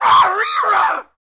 Index of /90_sSampleCDs/E-MU Producer Series Vol. 3 – Hollywood Sound Effects/Human & Animal/Dogs